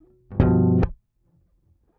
Index of /90_sSampleCDs/PowerFX - Nu Jazz House/120/120_Bass_Loops/120_PBass_C
120_PBass_Syncopated_Cho~ib.wav